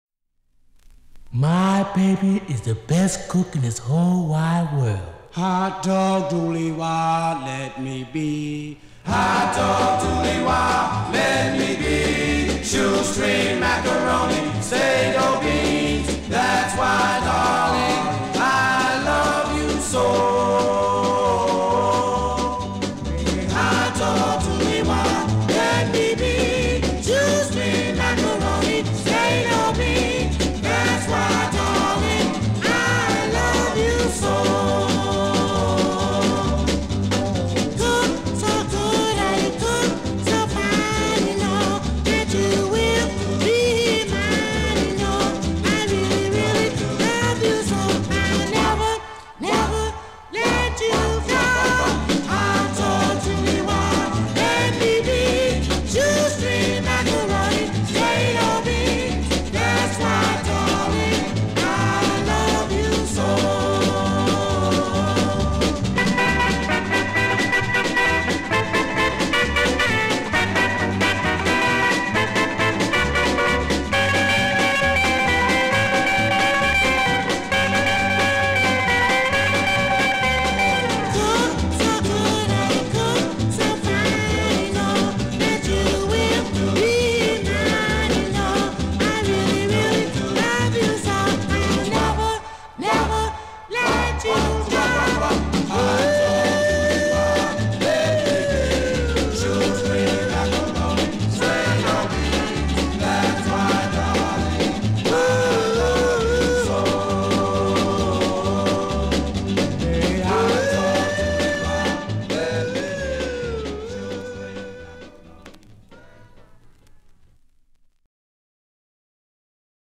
Tag: Doo Wop